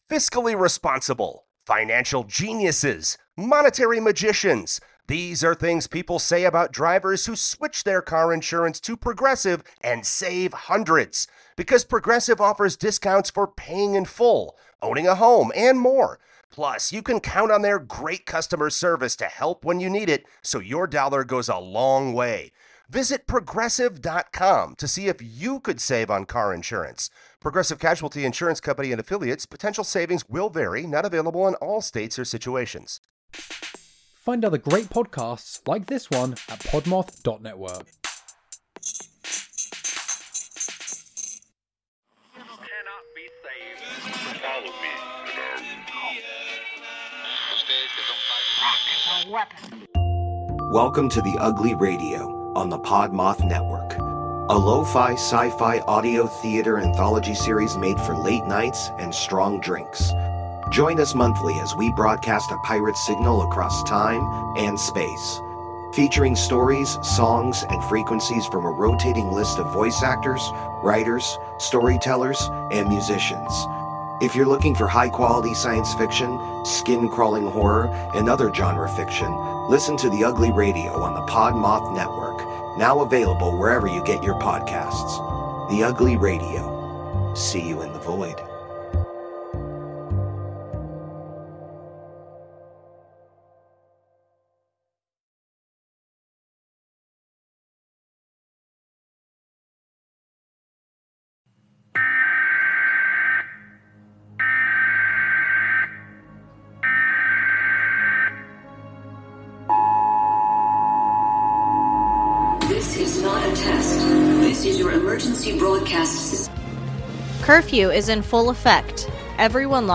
This is the podcast where we talk about brutal crimes, bizarre occurrences, and get you drunk with cocktails themed around one of our stories. Our story tellers are a mom and son combo who will bring you brutal and bizarre stories in a unique and fun way.